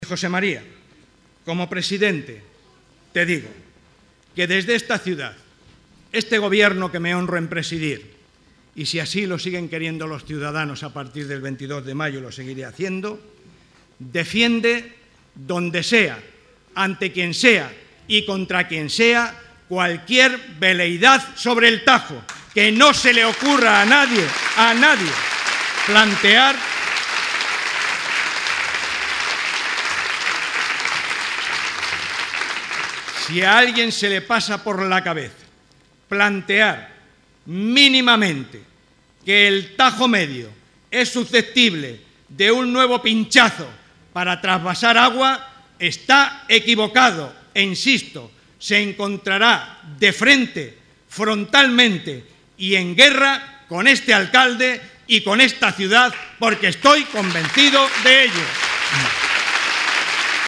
Barreda junto a Rivas en el acto celebrado en Talavera.